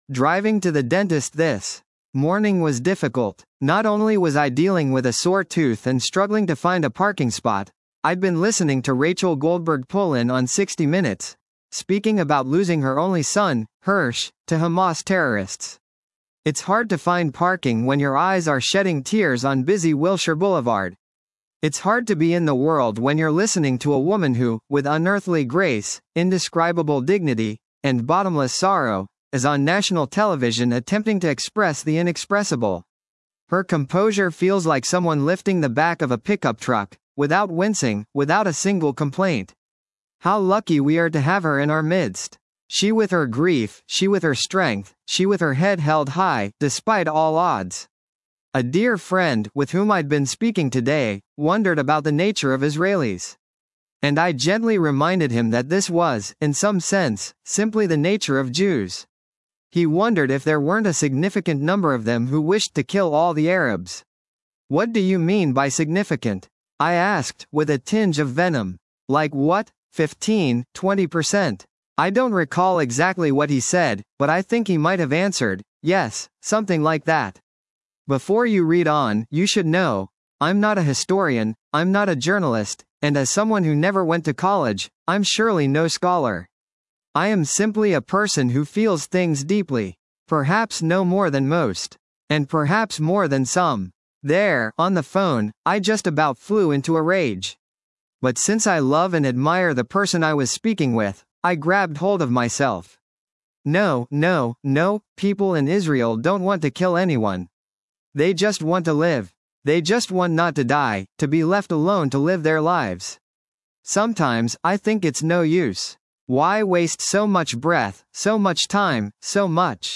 It’s hard to be in the world when you’re listening to a woman who, with unearthly grace, indescribable dignity, and bottomless sorrow, is on national television attempting to express the inexpressible. Her composure feels like someone lifting the back of a pickup truck, without wincing, without a single complaint.